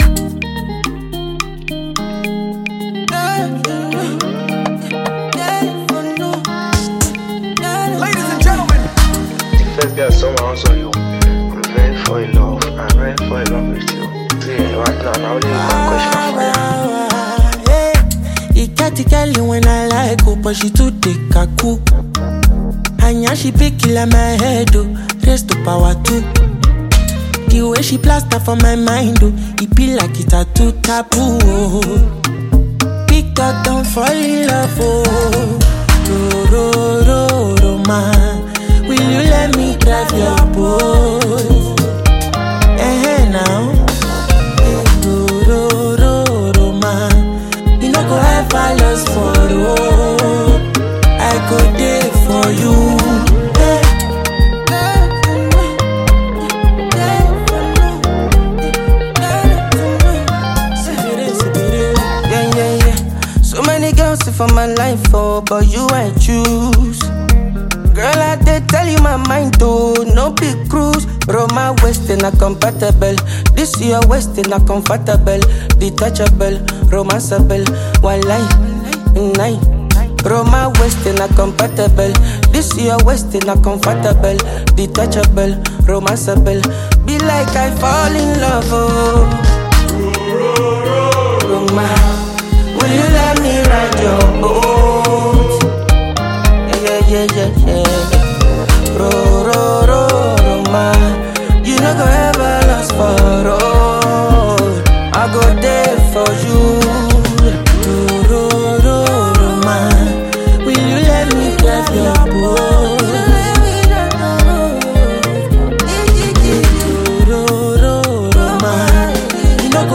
Highly talented Ghanaian Afrobeat singer